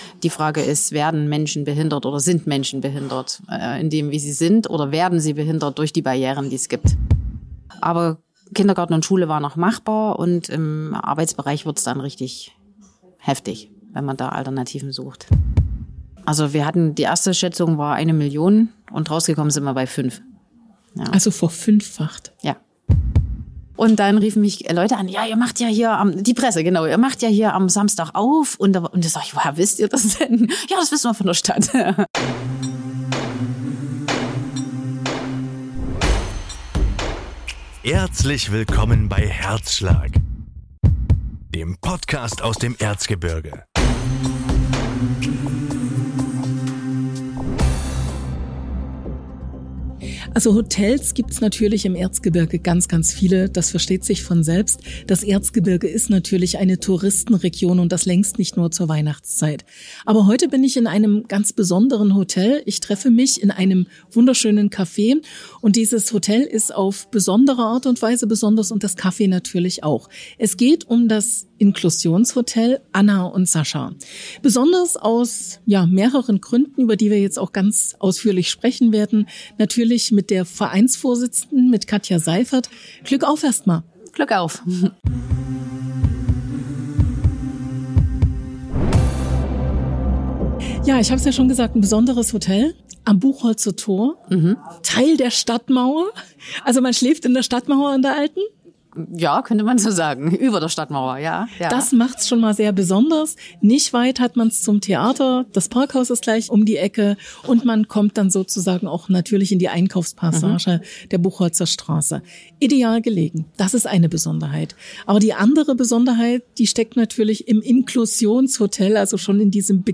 Es klappert in der Küche. Geschirr wird geräumt. In den Pfannen brutzelt es. In den Töpfen kocht es. Ganz normale Geräusche in einer Hotelküche.